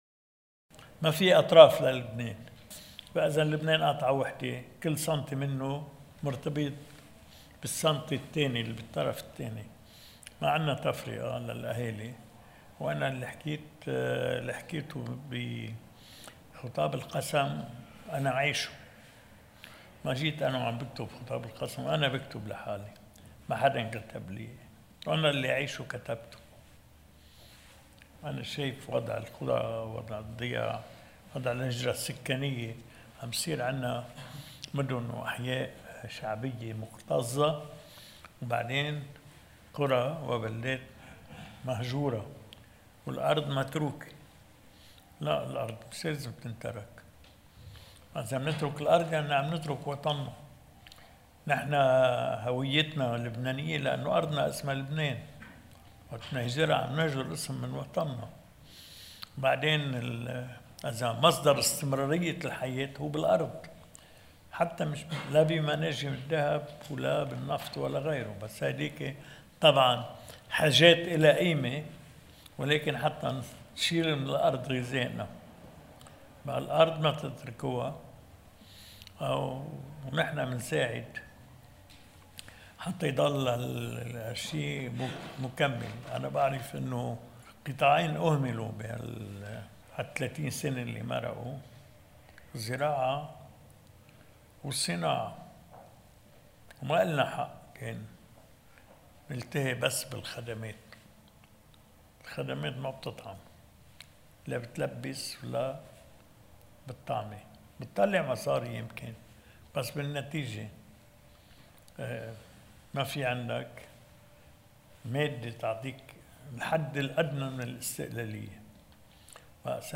مقتطف من حديث الرئيس عون أمام وفد من الحراك المدني في عكار وطرابلس: